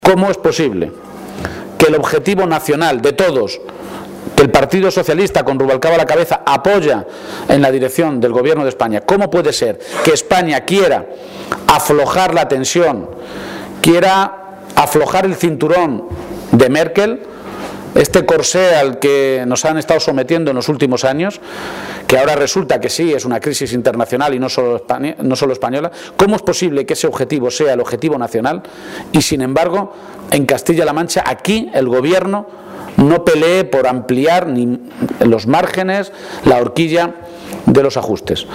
García-Page ha realizado estas declaraciones en Puertollano, localidad en la que esta tarde tiene previsto reunirse con colectivos y sindicatos para analizar la tremenda situación por la que está atravesando la ciudad industrial.